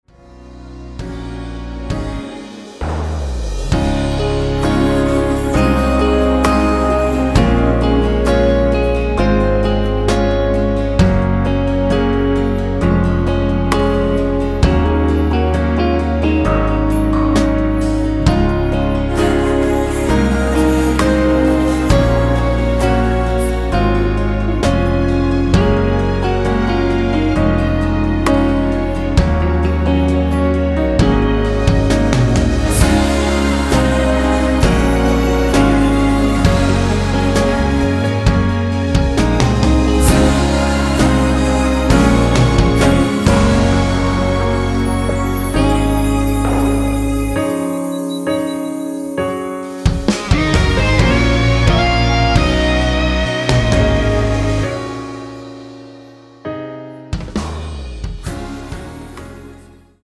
키 Bb